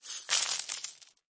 get_coin2.mp3